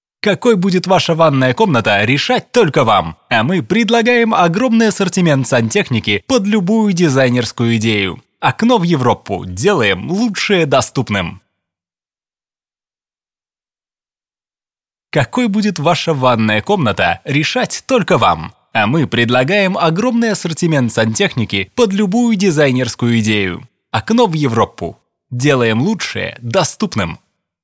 Микрофон: Samson C01 Studio Condenser